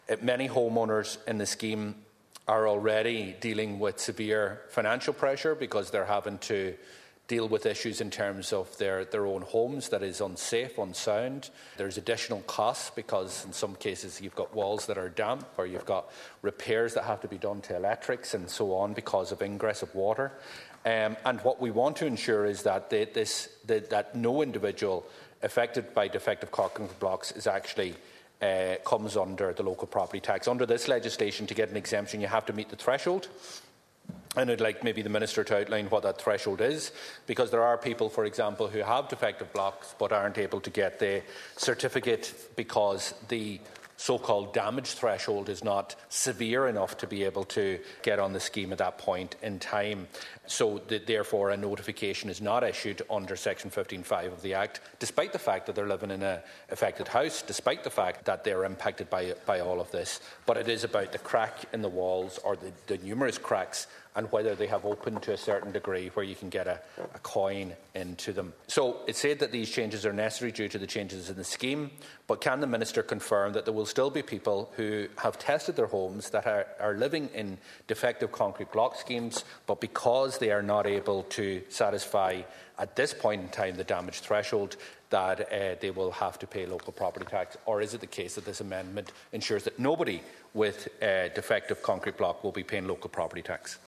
He told Minister Paschal Donohoe in the Dail that many homeowners are in a catch twenty two situation as they fail to meet the damage threshold to avail of the exemption: